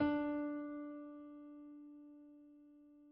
/piano/D4.mp3